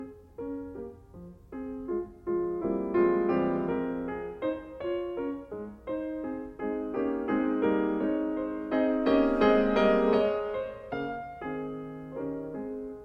Second movement – Adagio, F major, modified sonata form
Let us listen to this wonderful music that is like singing, very light and elevated in mood.